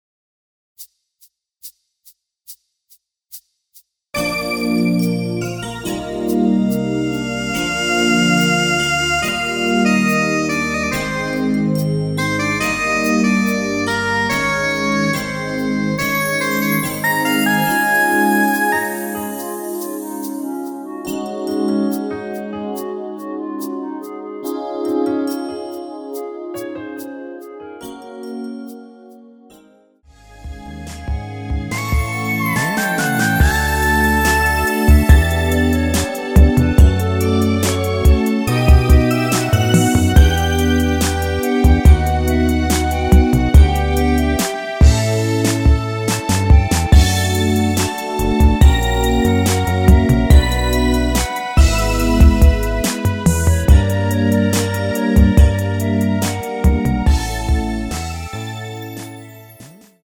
Db
노래방에서 노래를 부르실때 노래 부분에 가이드 멜로디가 따라 나와서
앞부분30초, 뒷부분30초씩 편집해서 올려 드리고 있습니다.
중간에 음이 끈어지고 다시 나오는 이유는